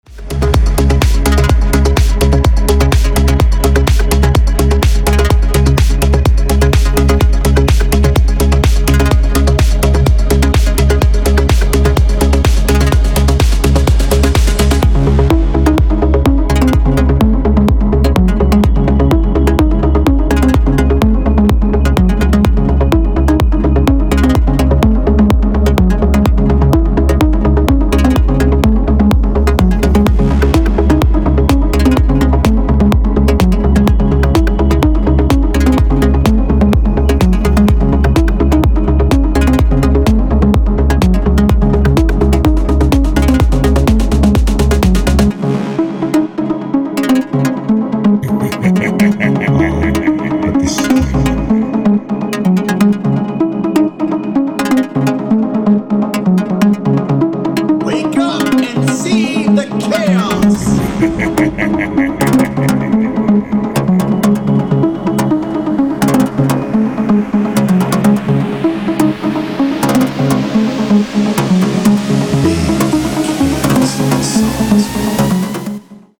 Melodic House & Techno